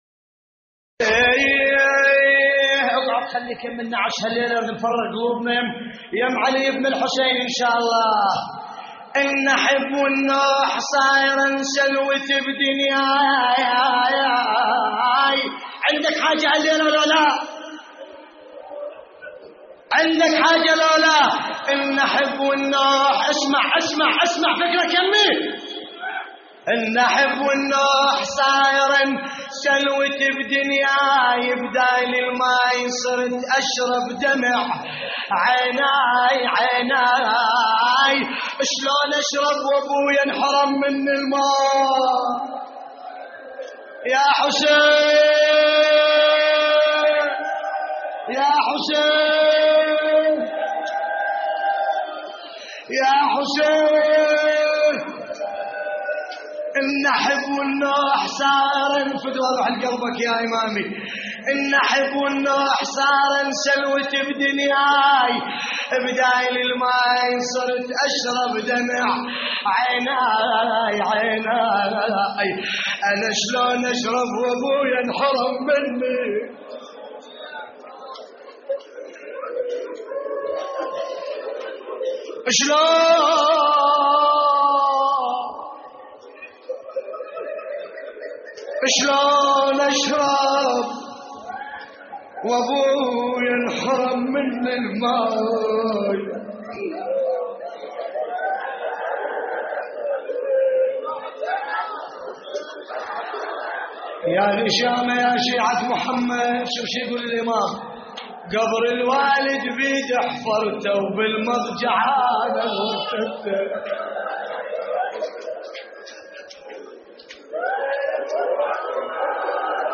نعي وأبوذيات